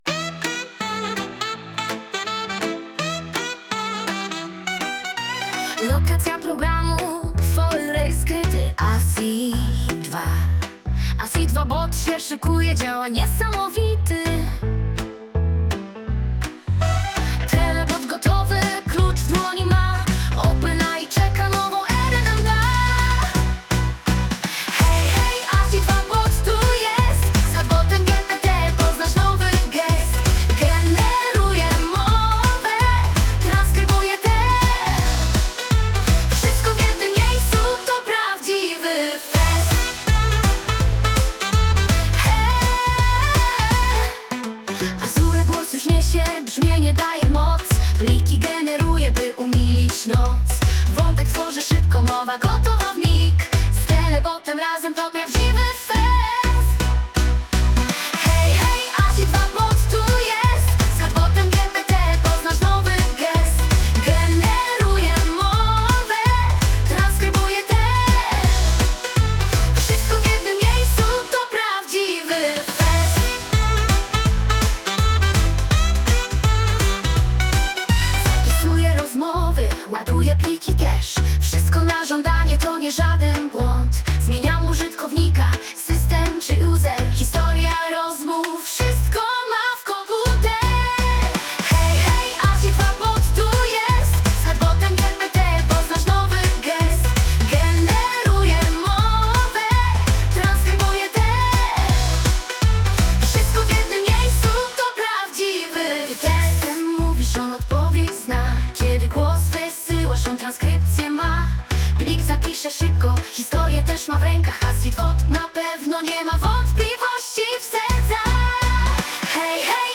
bot telegram Asi2bot electroswing.mp3